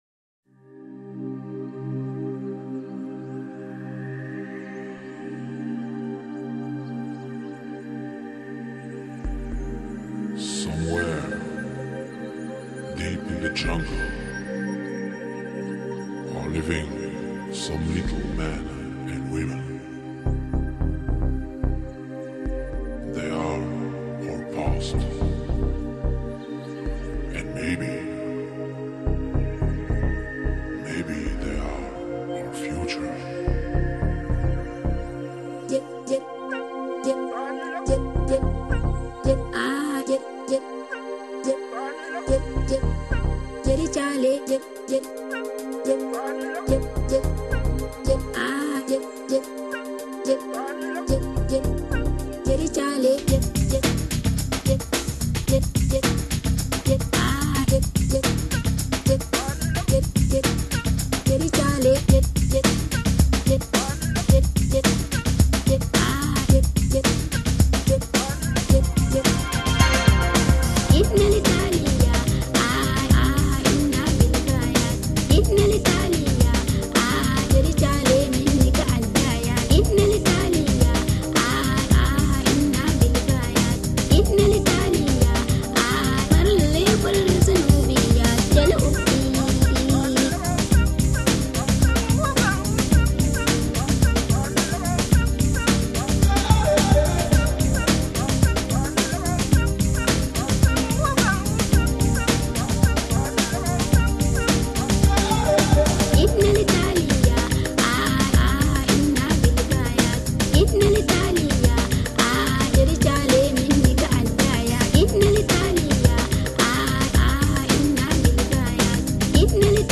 将其融入到现代电子键盘乐器所创造的ambient氛围音乐中，并辅以强烈的舞曲与流行节拍，创造出无数独特而动听的作品